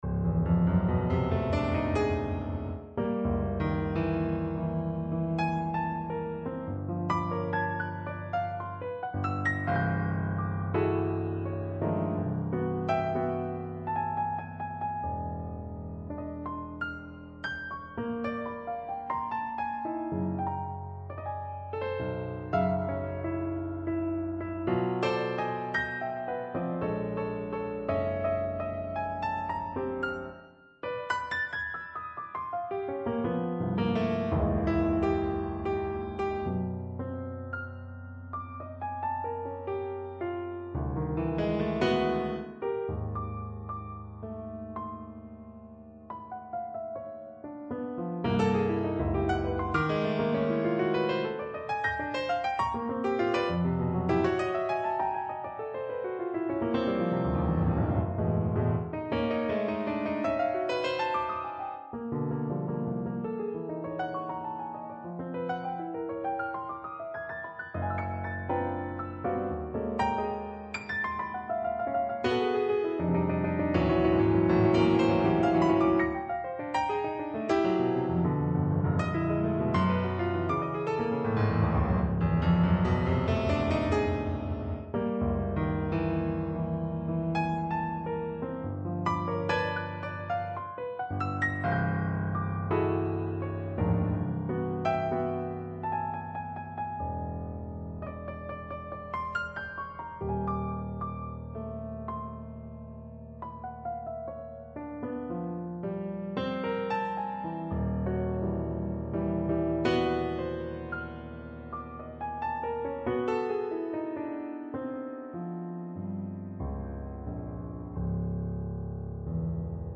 for solo piano